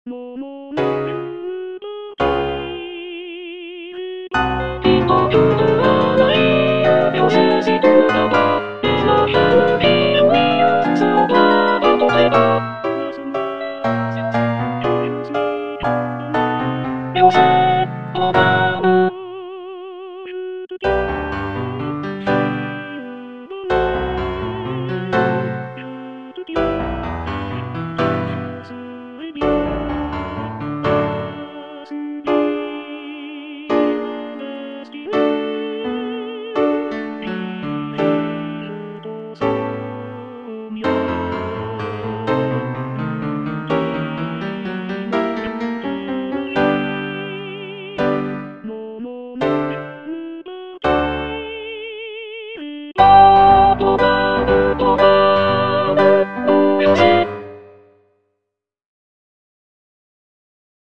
G. BIZET - CHOIRS FROM "CARMEN" Il t'en coutera la vie (soprano I) (Emphasised voice and other voices) Ads stop: auto-stop